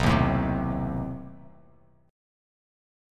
B7b9 Chord
Listen to B7b9 strummed